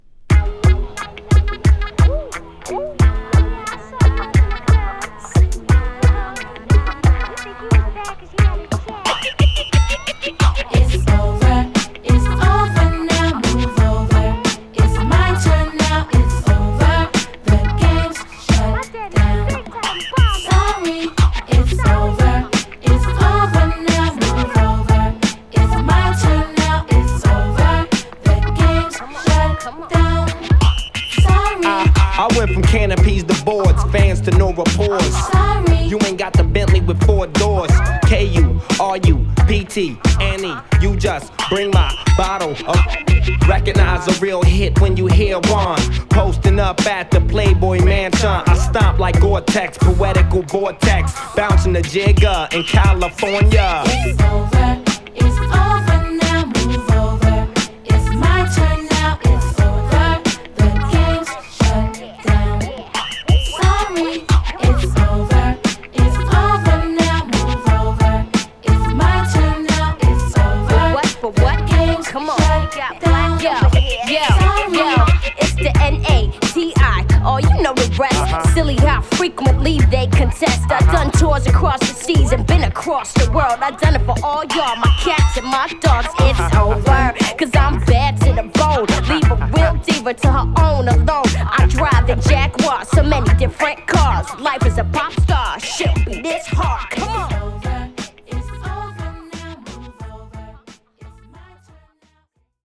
コミカルタッチなトラックにキュートな女性コーラスのフックがGOODでギャル受け抜群。